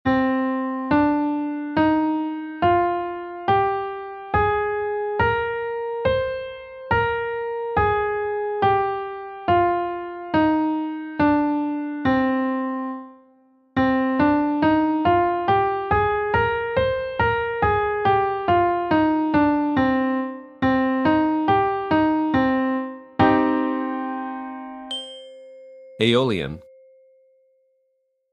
Ear Training - Ionian vs. Aeolian Modes